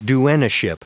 Prononciation du mot duennaship en anglais (fichier audio)
Prononciation du mot : duennaship